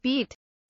beat kelimesinin anlamı, resimli anlatımı ve sesli okunuşu